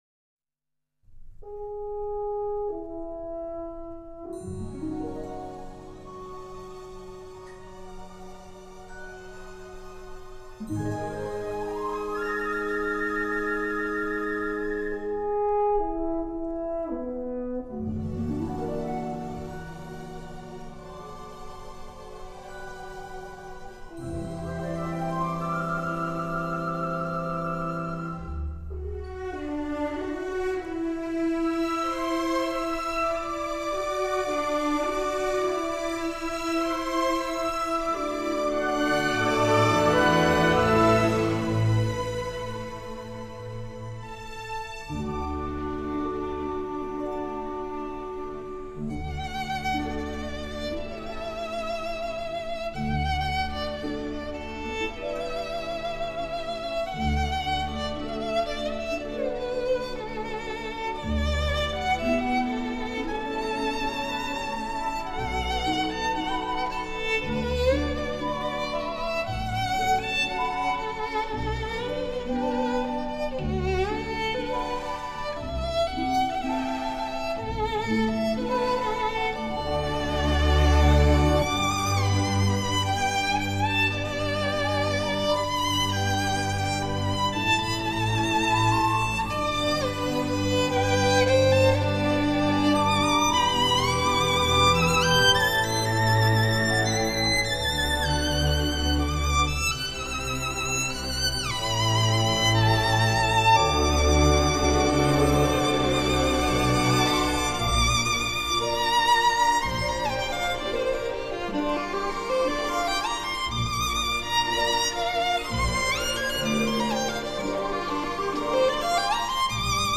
※清新质朴 浪漫抒情※
如同这个美丽而又简单的传说一样，这部小提琴协奏曲采用了单乐章回旋曲式结构，但结合了变奏曲及奏鸣曲的一些特点。
乐曲和声清新，复调简洁，配器色彩艳丽。
试听为 截段的低音质